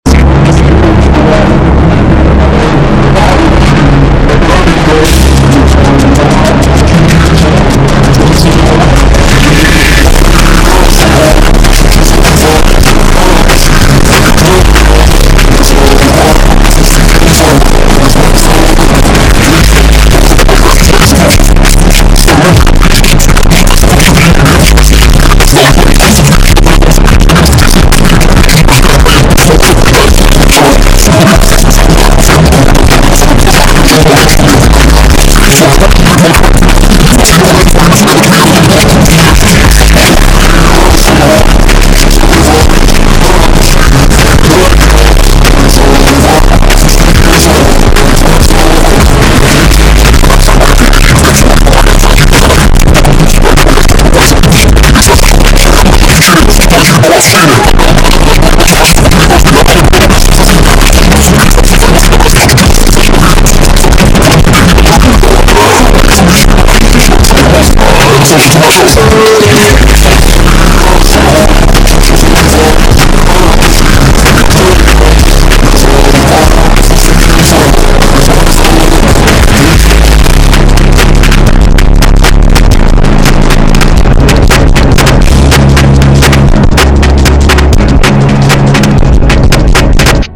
Звуки противные для соседей
Адские звуки ужаса